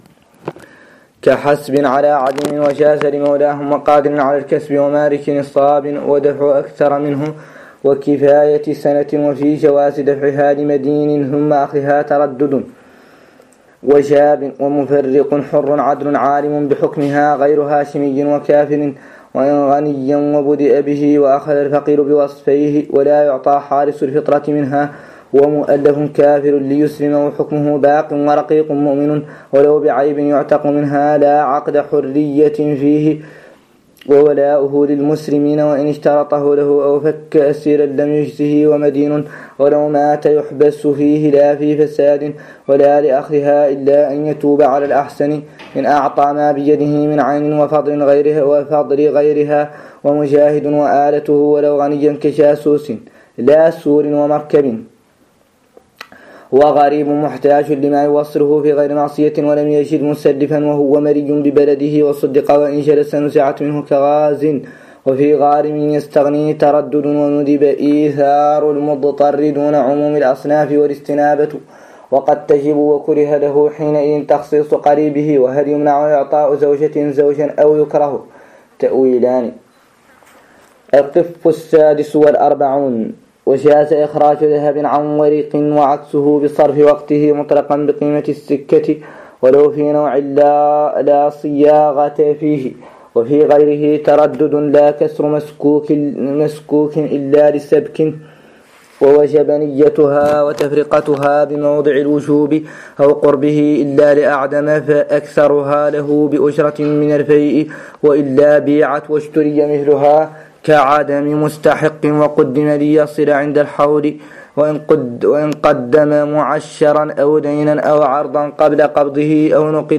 قراءة لمتن مختصر خليل 03